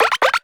cartoon_boing_climb_run_03.wav